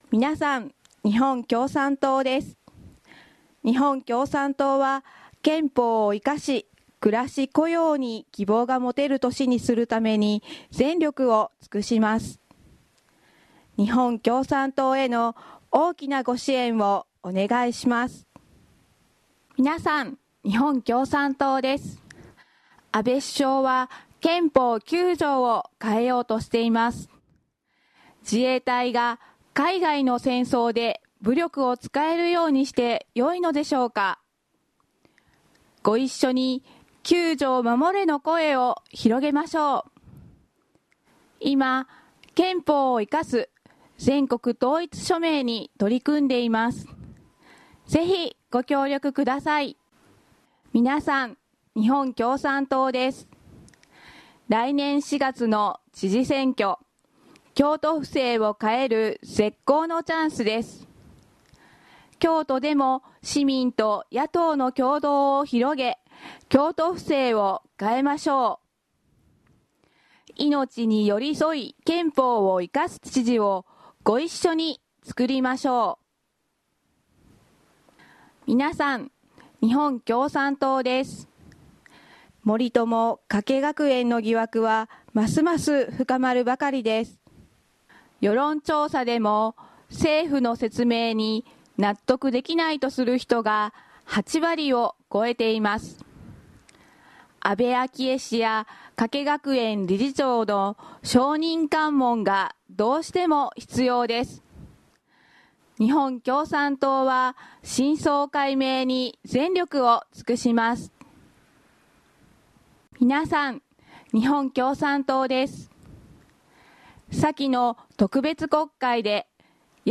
流しスポット例